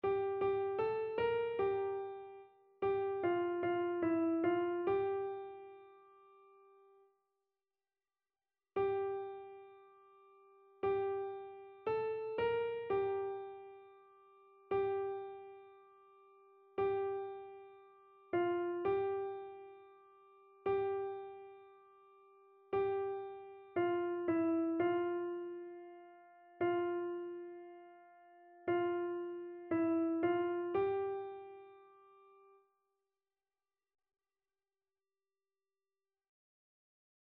Chœur
SopranoAlto
annee-a-temps-ordinaire-29e-dimanche-psaume-95-soprano.mp3